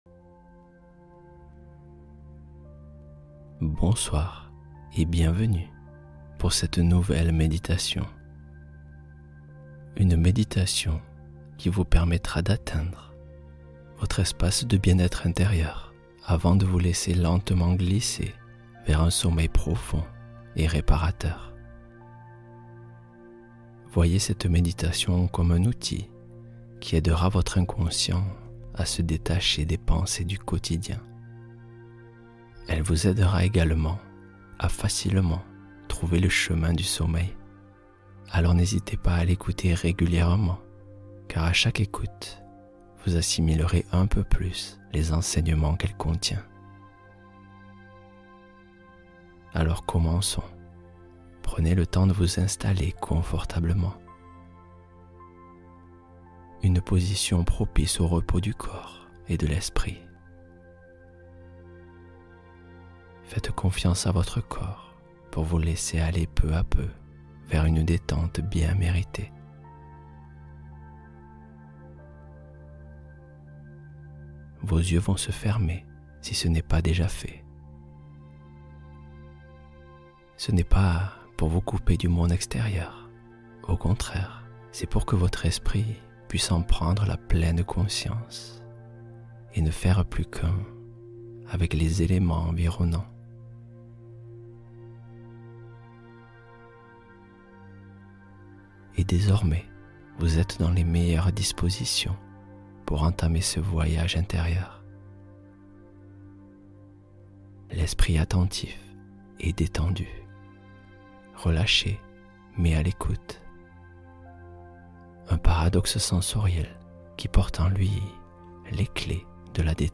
Sommeil facilité — Méditation du soir pour ralentir